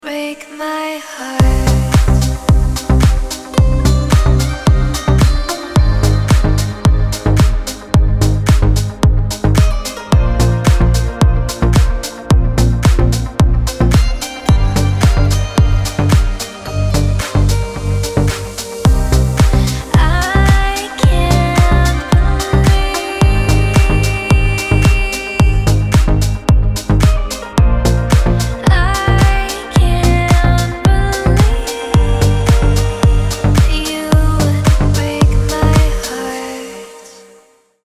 Стиль: deep house